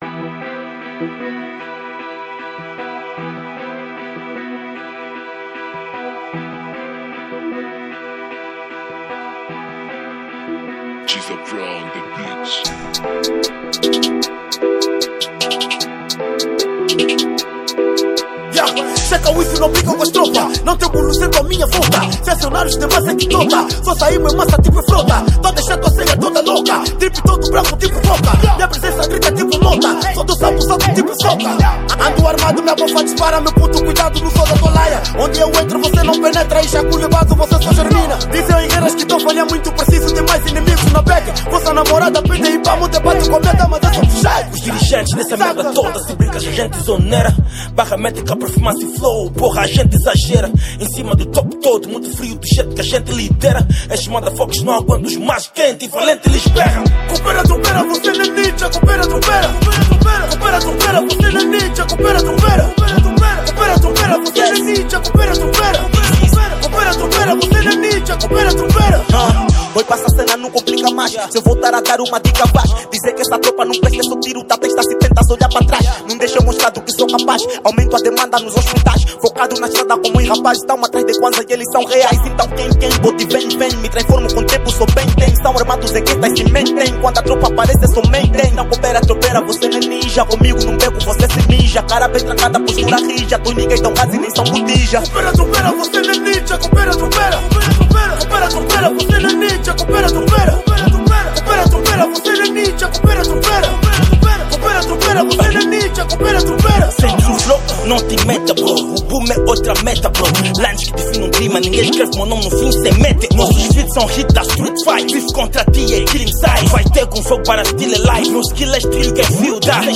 | Trap